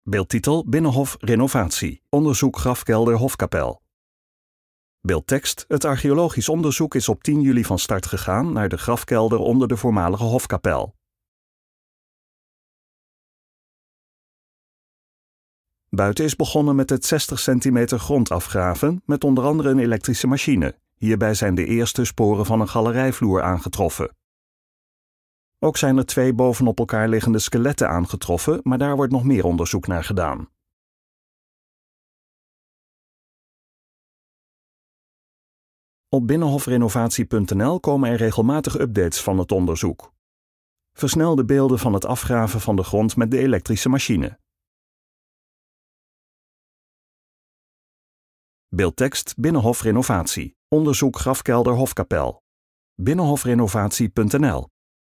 OPGEWEKTE MUZIEK TOT HET EIND VAN DE VIDEO